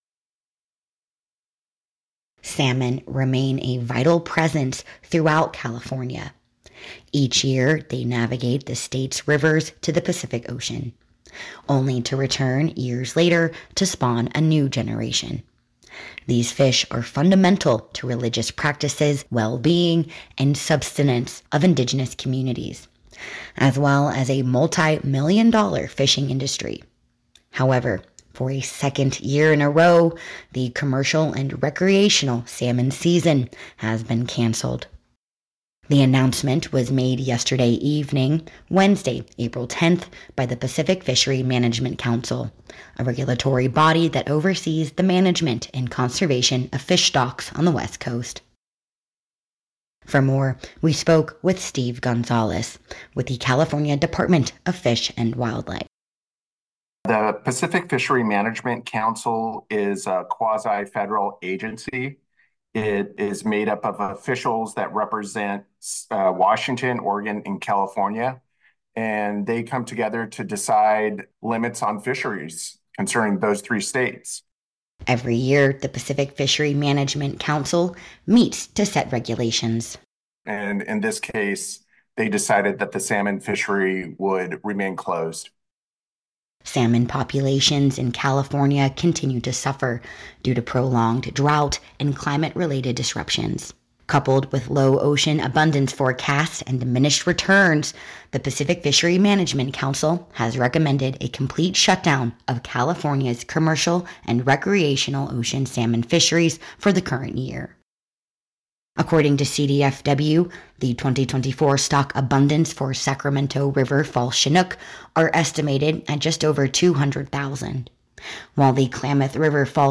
in-depth report